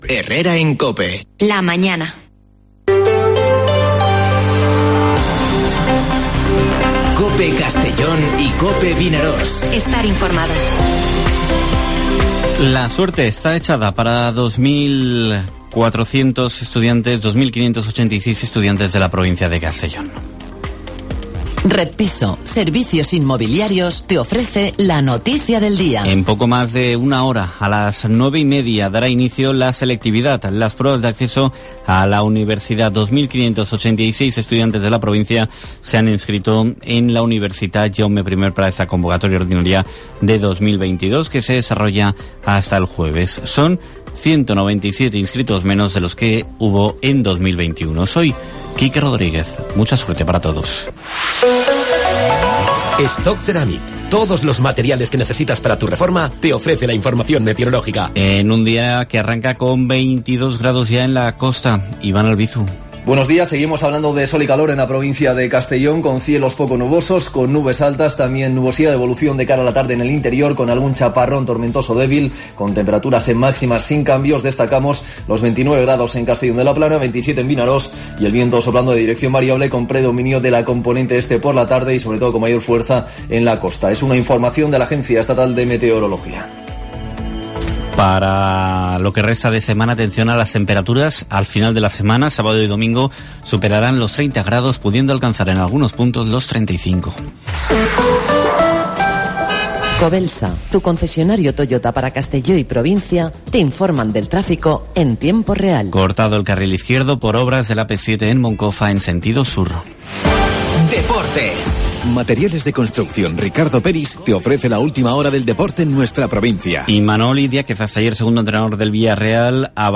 Informativo Herrera en COPE en la provincia de Castellón (07/06/2022)